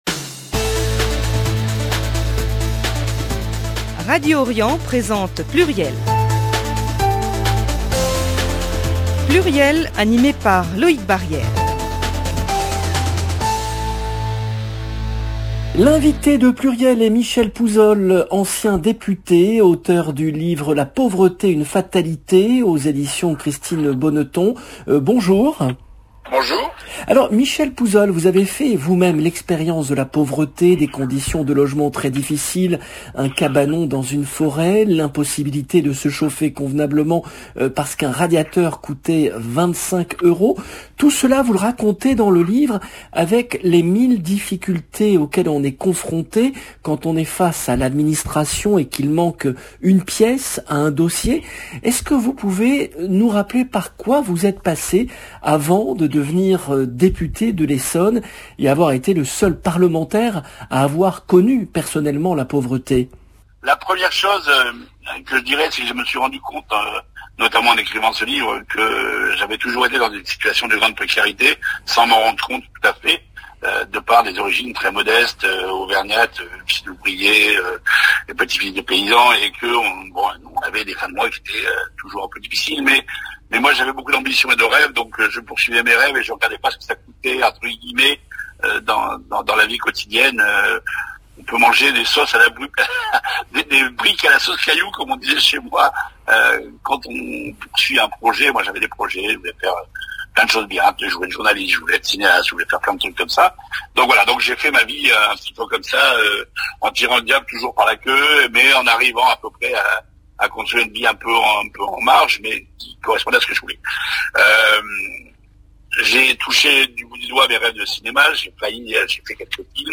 PLURIEL, le rendez-vous politique du mercredi 9 février 2022